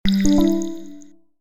Waterflow
Waterflow.mp3